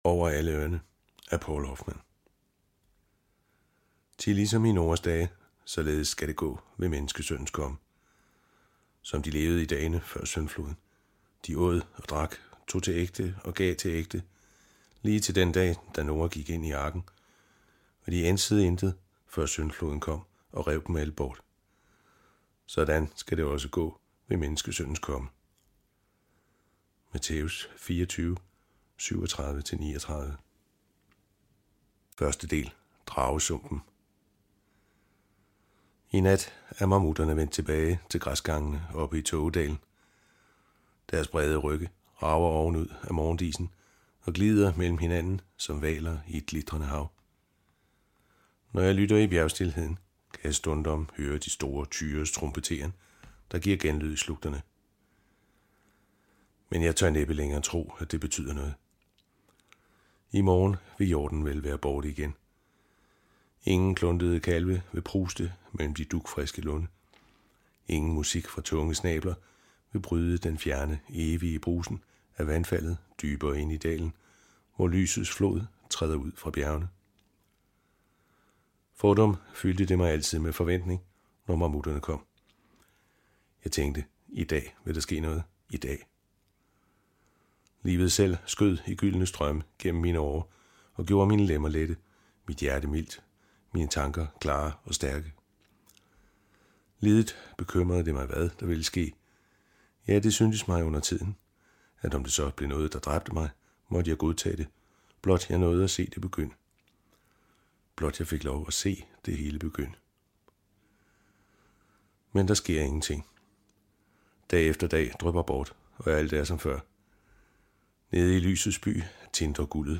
Hør et uddrag af Over alle ørne Over alle ørne Noas dage I Format MP3 Forfatter Poul Hoffmann Lydbog E-bog 149,95 kr.